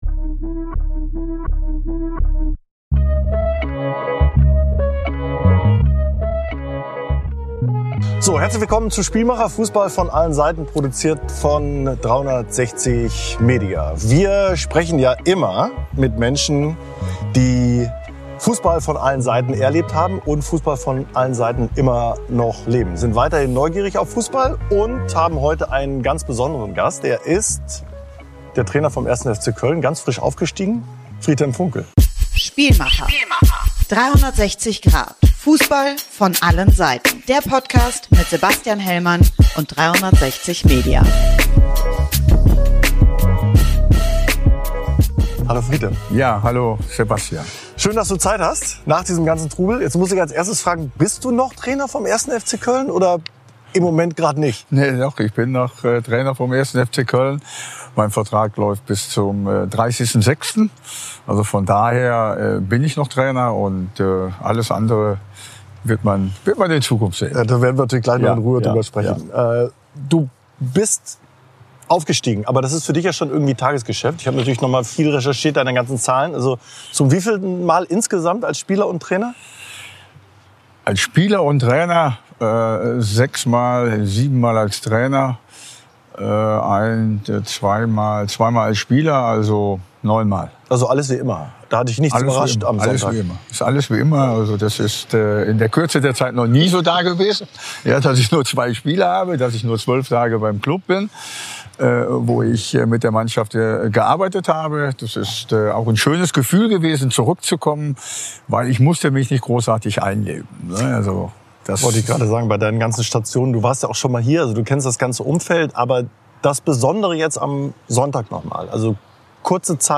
Beschreibung vor 11 Monaten Diese Folge ist voller frischer Emotionen - und hochinteressanter Aussagen und Einschätzungen: Sebastian Hellmann hat sich mit FC-Trainer Friedhelm Funkel dort getroffen, wo ihm vor wenigen Tagen der Aufstieg mit dem 1.FC Köln in die Bundesliga geglückt ist: Im Mittelkreis des Rheinergie-Stadions. Das Bier ist gerade erst aus den Haaren gewaschen - bei „Spielmacher“ lässt Funkel die letzten 15 Minuten vor Abpfiff und die Feierlichkeiten danach nochmal Revue passieren.